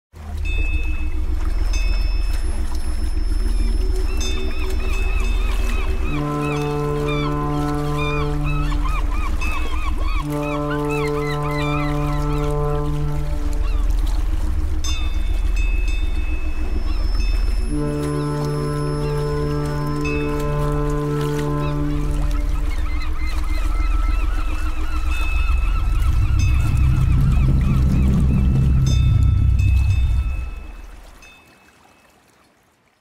Звуки маяка
Шум волн у маяка